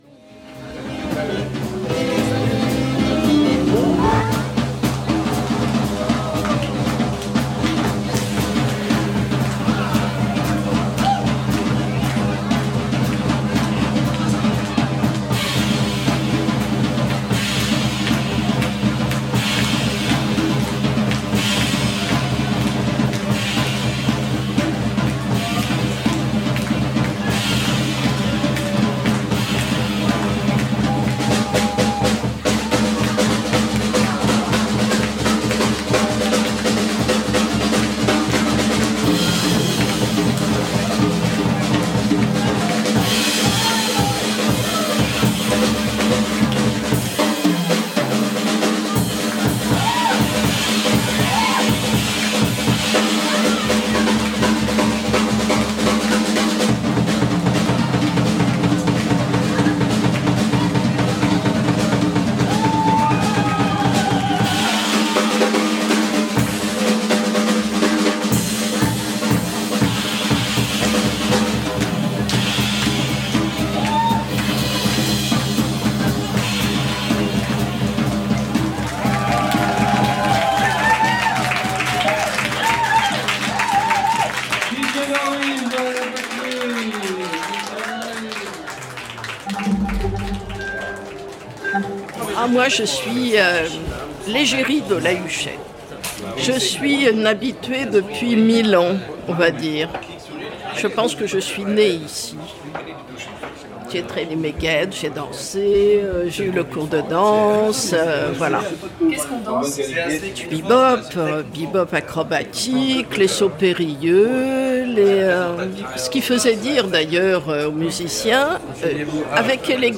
Reportage enregistré le 17 septembre 2019,.